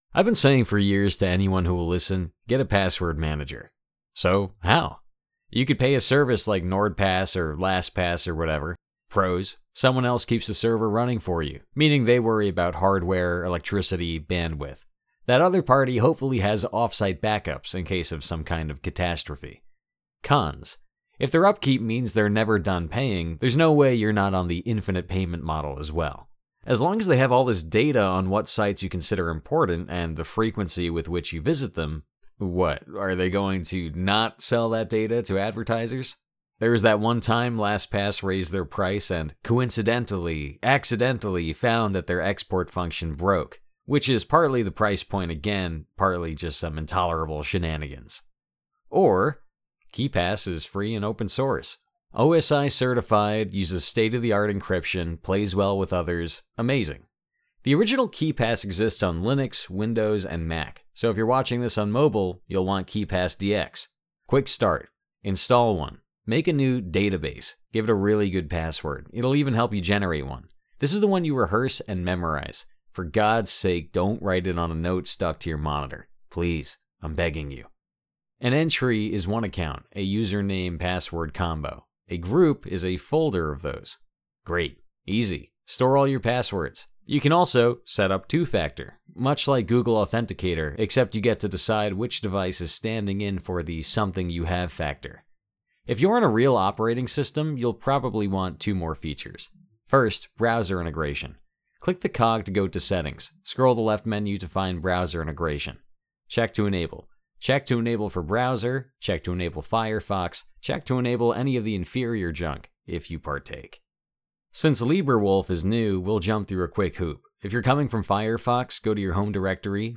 Password Manager Setup VO.wav